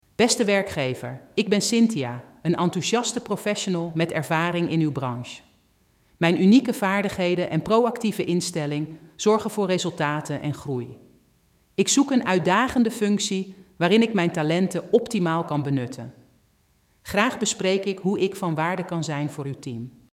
Voice AI Agents »
Cynthia-intro-new-EL.mp3